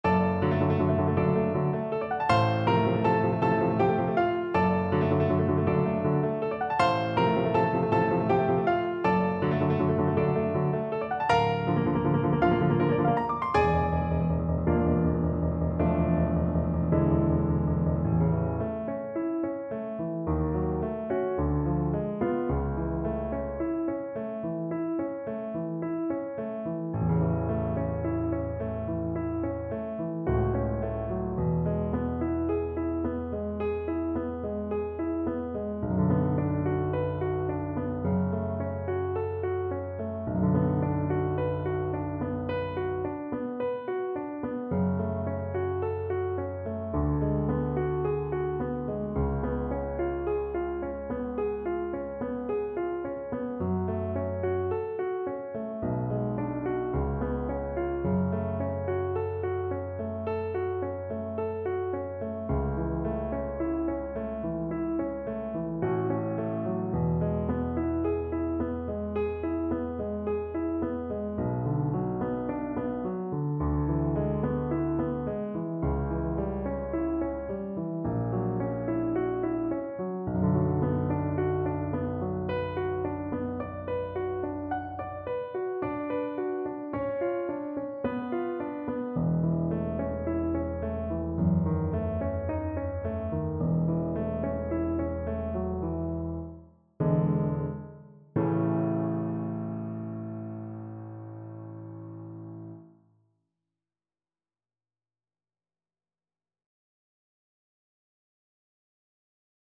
6/8 (View more 6/8 Music)
Sostenuto =160 Sostenuto
Classical (View more Classical Violin Music)